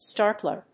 アクセント・音節stár・tler